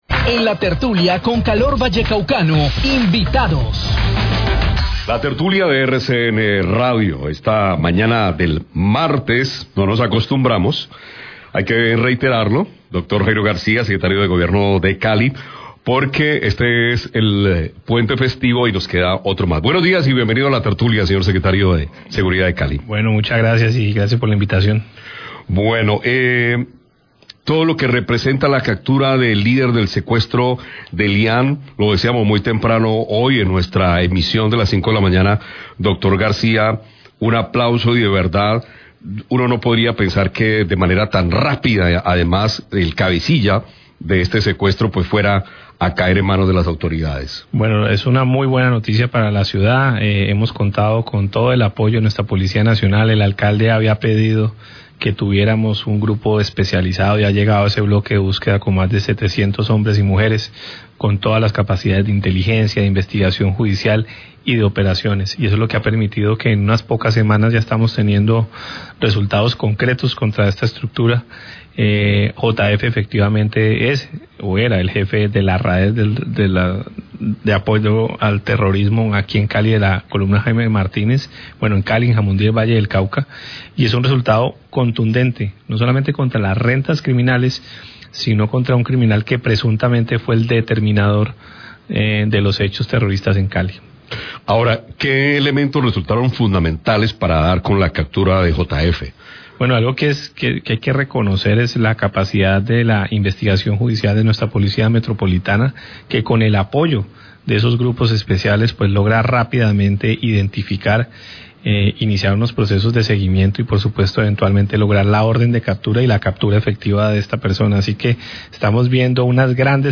Entrevista secretario de Seguridad de Cali, RCN Racio 1130am
entrevistas